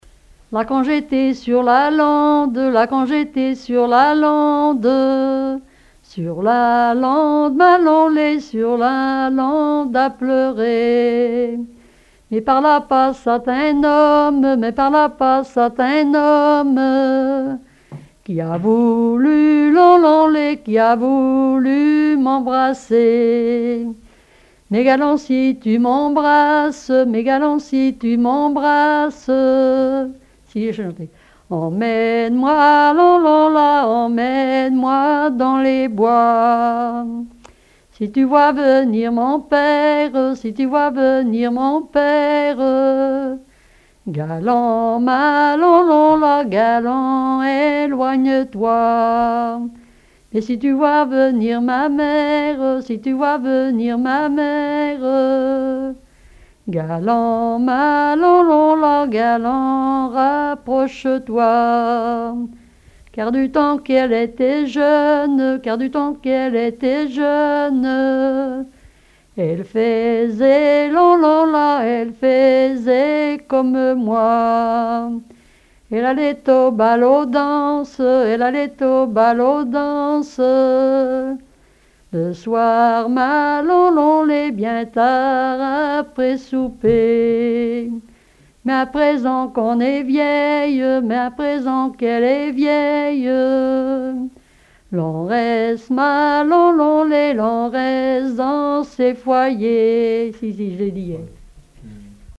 ronde à la mode de l'Epine
Témoignages et chansons traditionnelles
Pièce musicale inédite